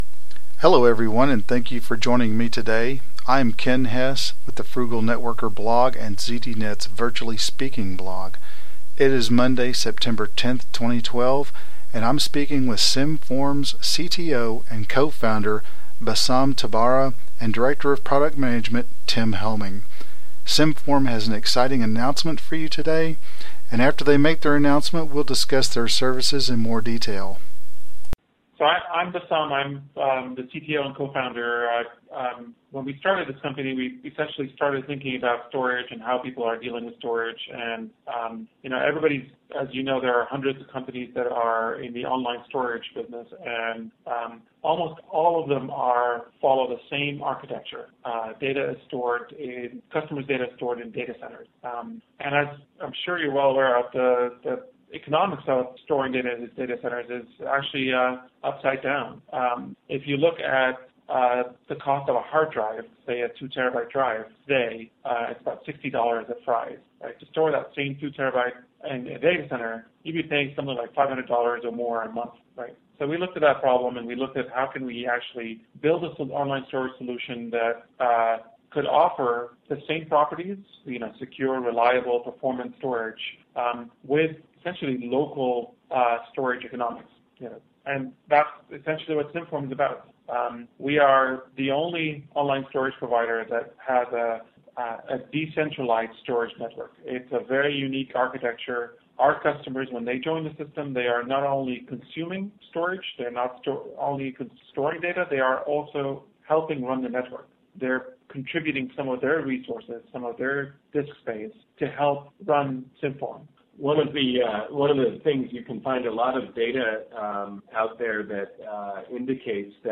Podcast discussion